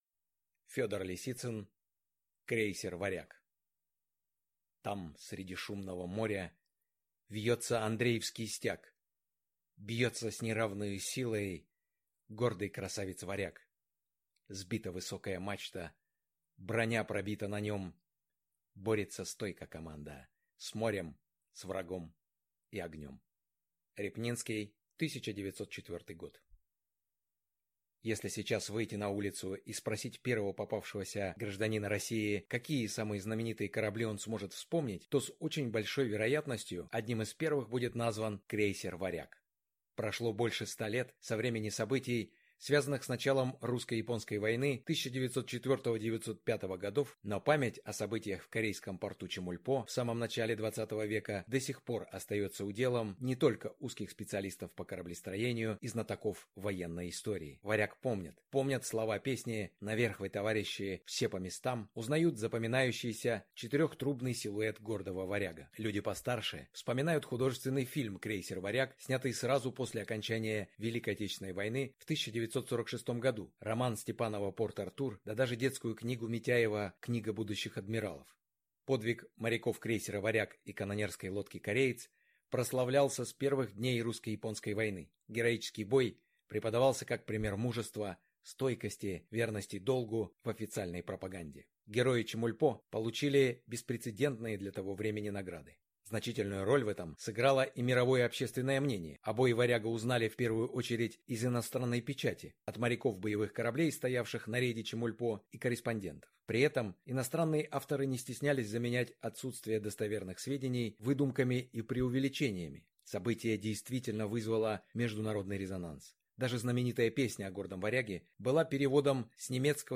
Аудиокнига Крейсер «Варяг» | Библиотека аудиокниг
Прослушать и бесплатно скачать фрагмент аудиокниги